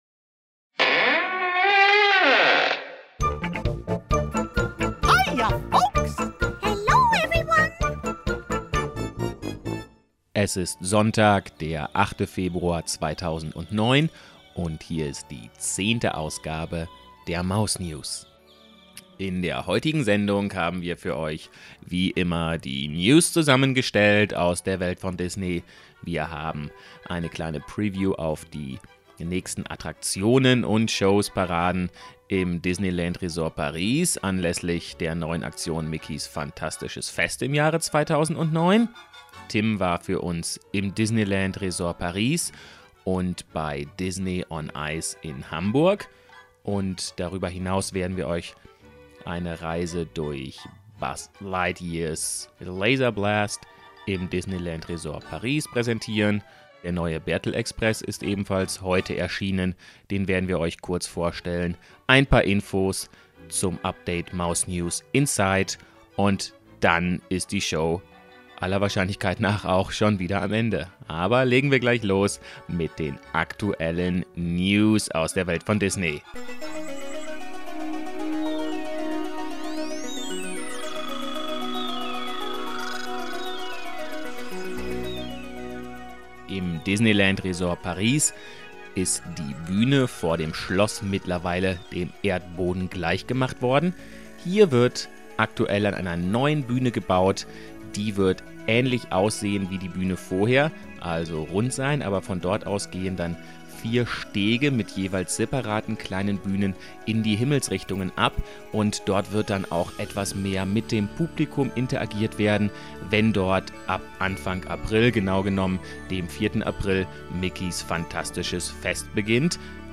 Live aus den Walt Disney Studios Paris: Buzz Lightyear Laser Blast